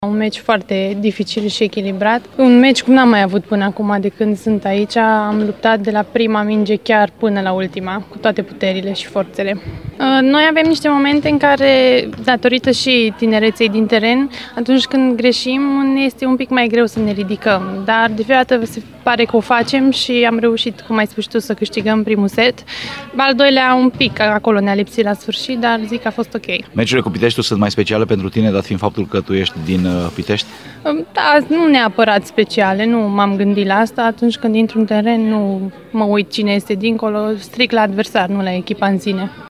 Declarații